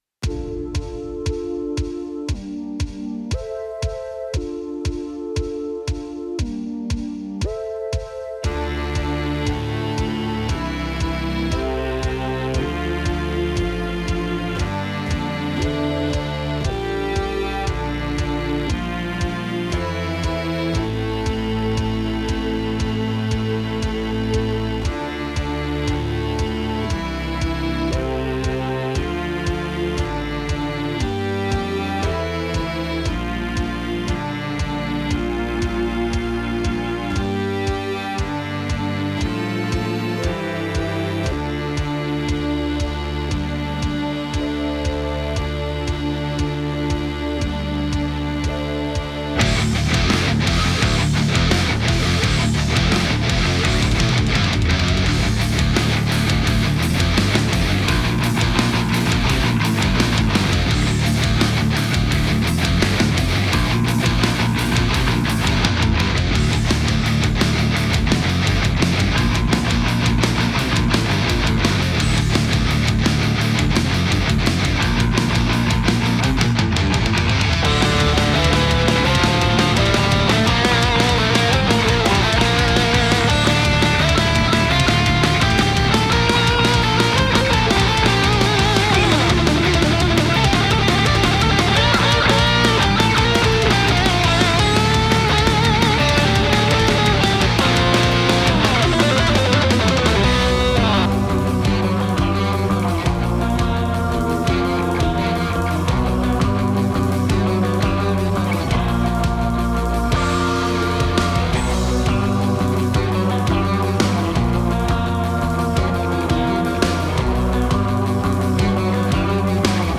гитары, барабаны, клавишные